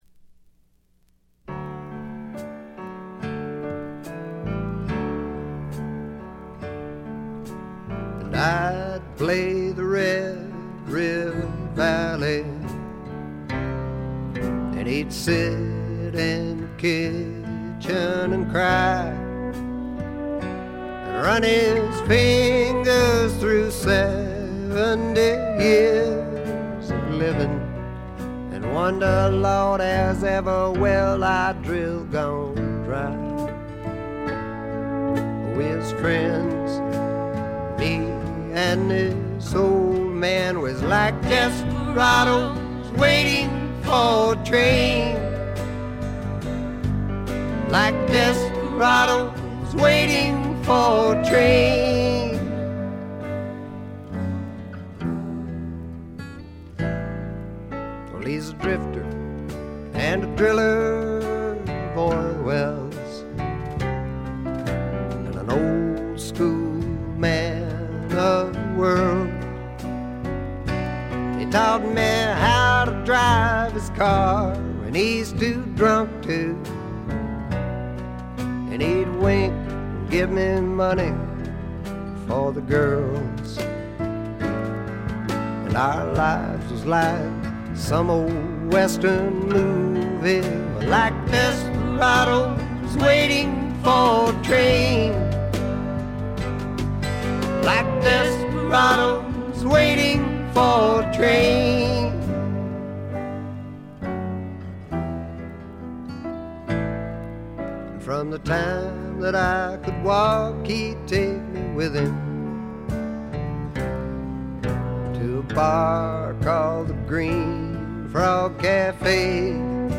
ほとんどノイズ感無し。
朴訥な歌い方なのに声に物凄い深さがある感じ。悲しみの表現がこれほど似合う声にはめったに出会えないと思います。
試聴曲は現品からの取り込み音源です。